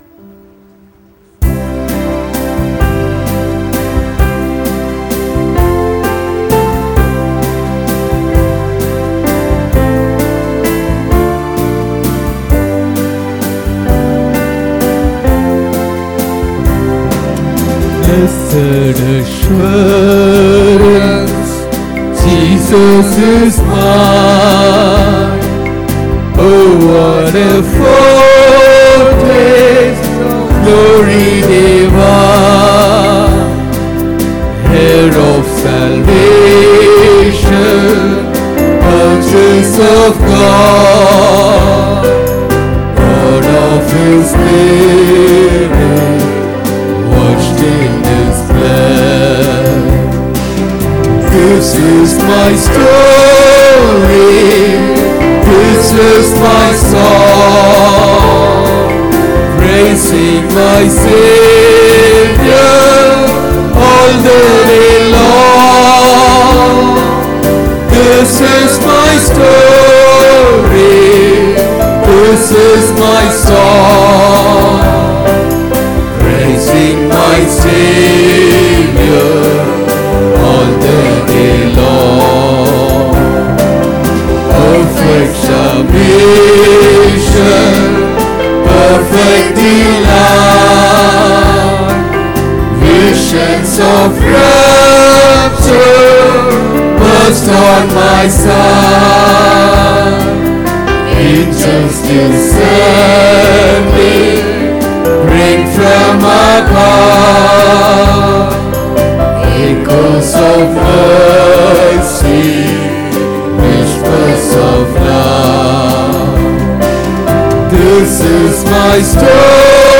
21 May 2023 Sunday Morning Service – Christ King Faith Mission